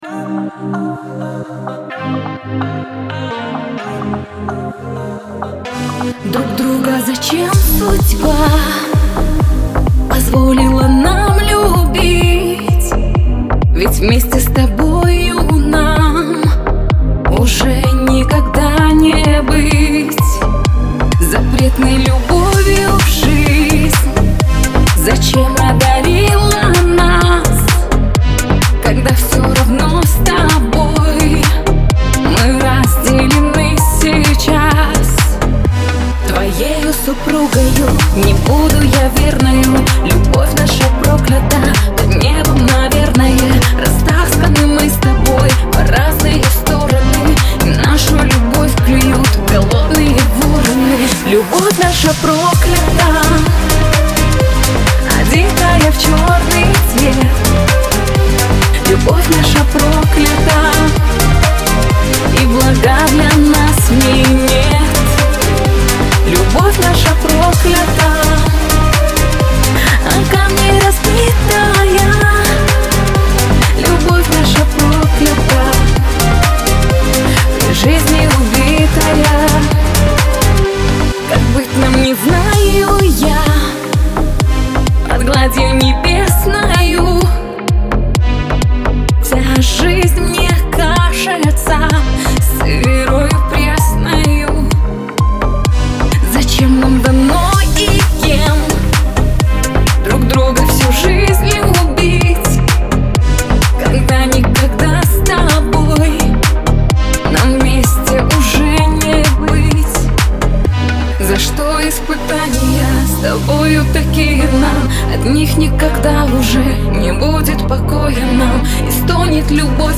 грусть
Кавказ – поп
Лирика